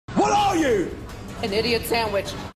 gordon-ramsay-idiot-sandwich-vine.mp3